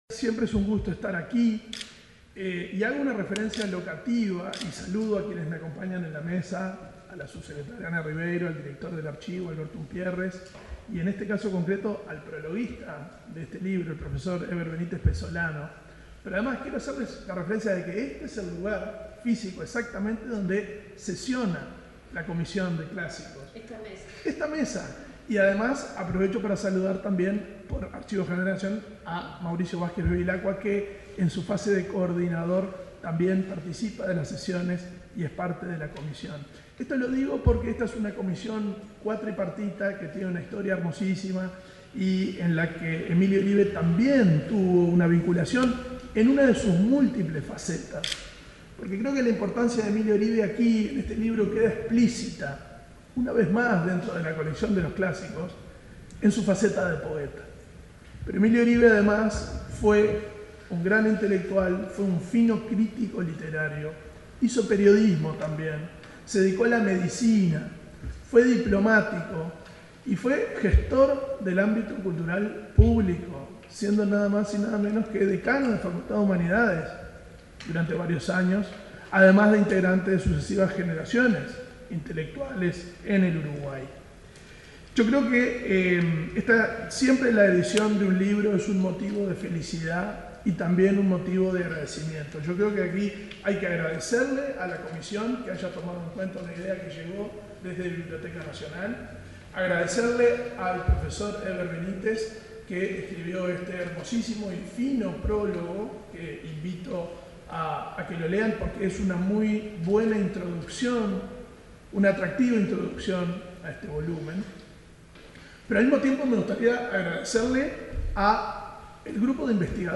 Palabras de autoridades del MEC
El director de la Biblioteca Nacional, Valentín Trujillo, y la subsecretaria del Ministerio de Educación y Cultura (MEC), Ana Ribeiro, participaron,